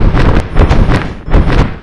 attack1_2.wav